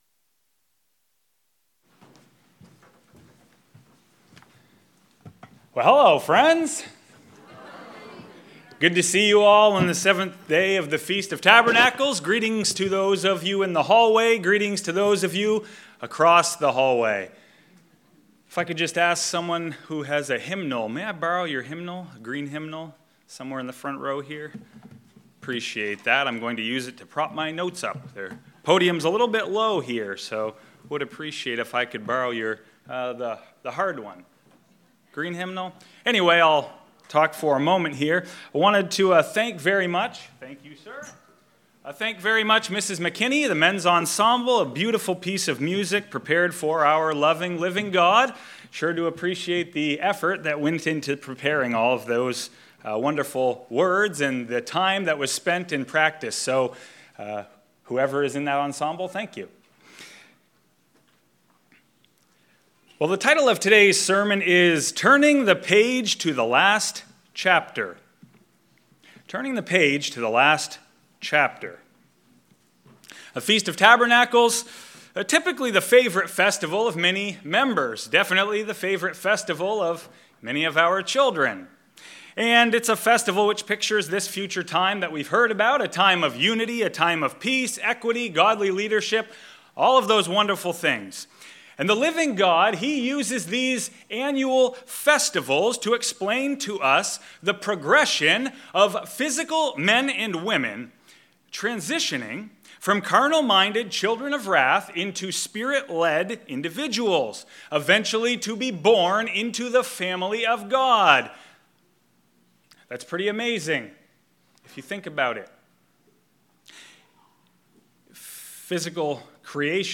This sermon was given at the Galveston, Texas 2023 Feast site.